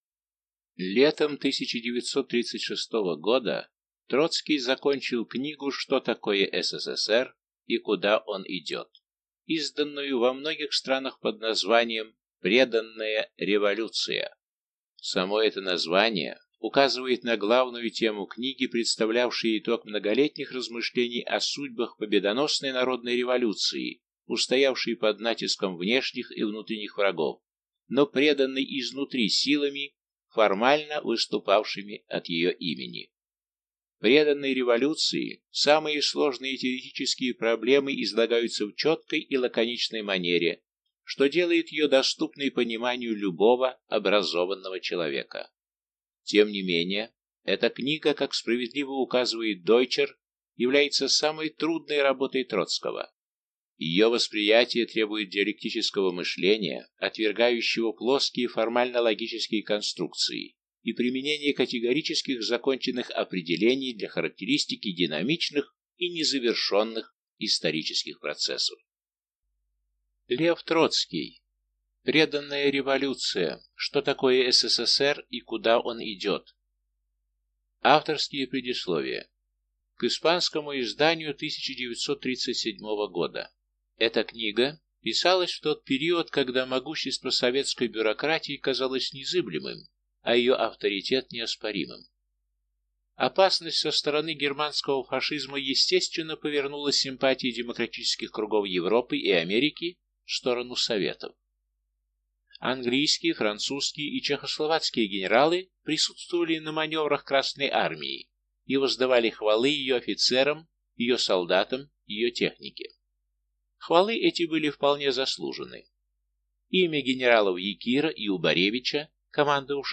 Аудиокнига Преданная революция: Что такое СССР и куда он идет? | Библиотека аудиокниг